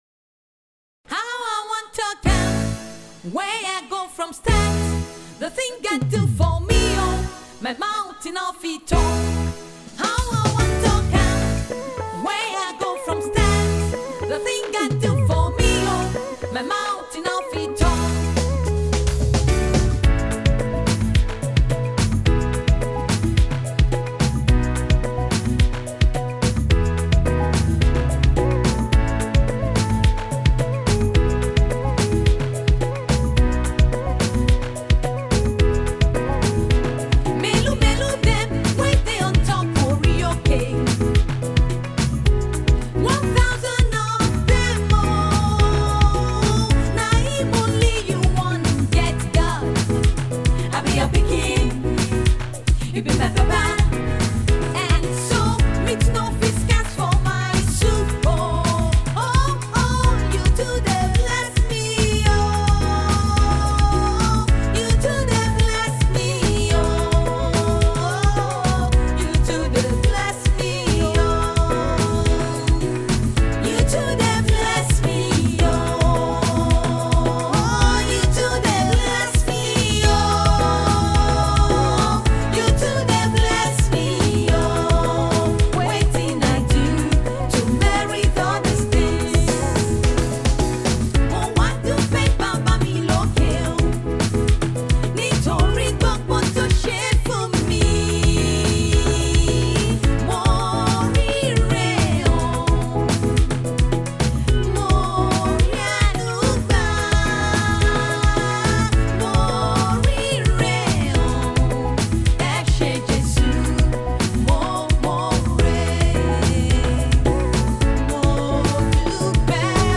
She is a spiritually gifted woman in terms of gospel music.